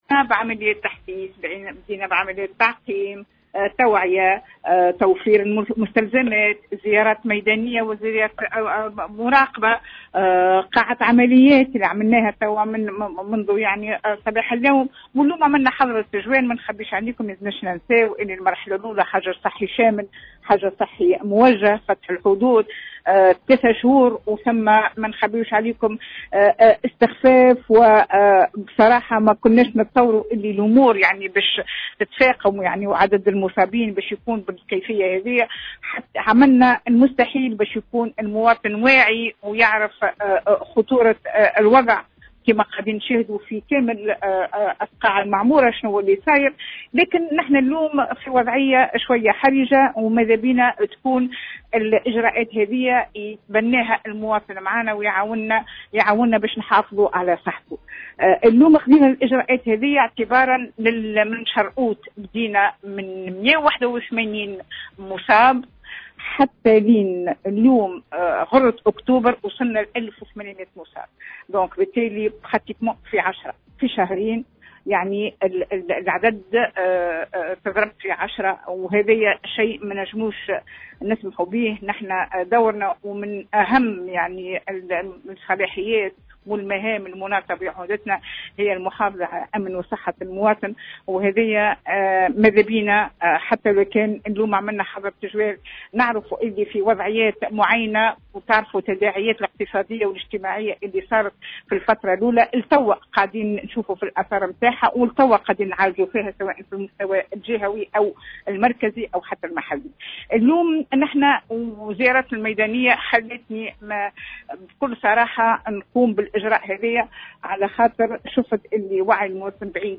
والية سوسة للجوهرة أف أم: قرار حظر التجول سيطبق بصرامة
أكدت والية سوسة رجاء الطرابلسي في تصريح للجوهرة أف أم، أن قرار اللجنة الجهوية لمجابهة الكوارث بسوسة بفرض التجوّل سيطبق بكل صرامة، مع تشديد الرقابة على تطبيق البروتوكول الصحي بعد ملاحظة استهتار كبير من قبل المواطنين، ما تسبب في انتشار الفيروس بطريقة "غير معقولة"، وفق تعبيرها.